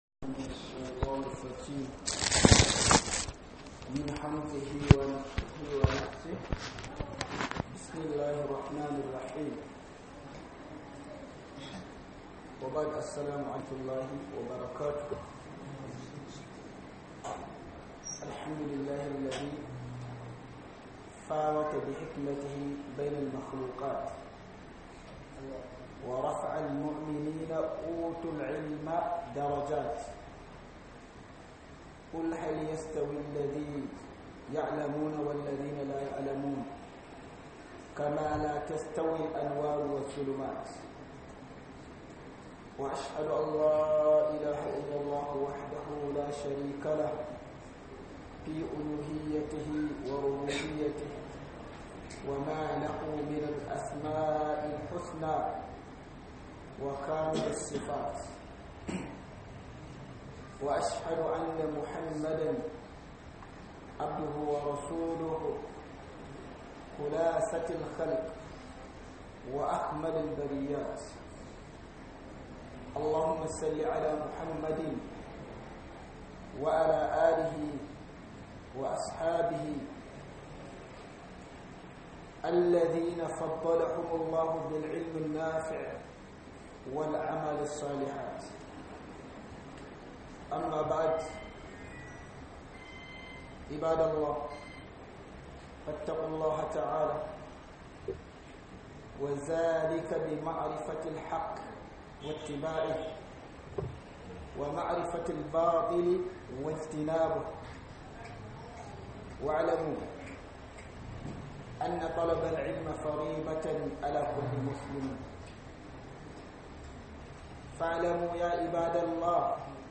Khudubar Sallar Juma'a